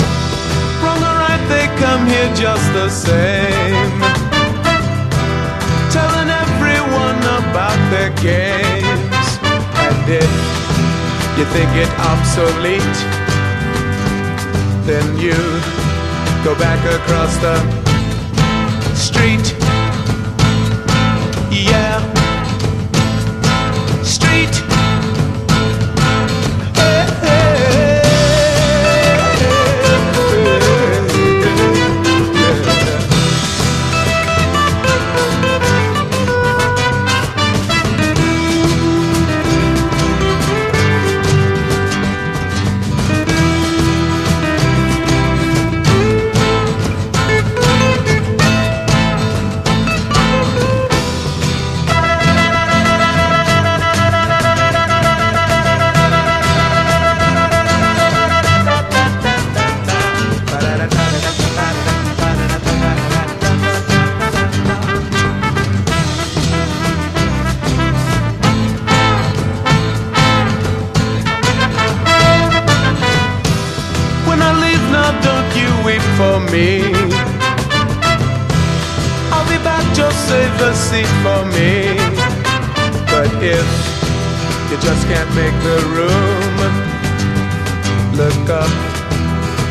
MID 80'S MOD POP！
弾けまくるバブルガムなメロディーとソフト・サイケ・ポップ風味がマッチした、まるでモンキーズなポップ・チューンがぎっしり！